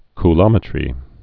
(k-lŏmĭ-trē)